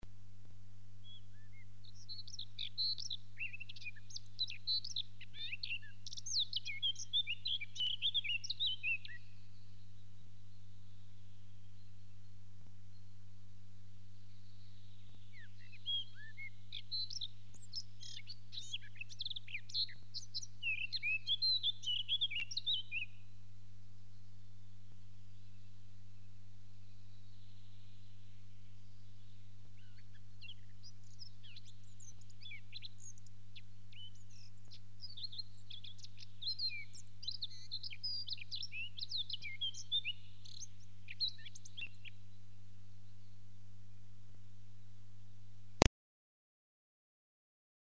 Penice_cernohlava.mp3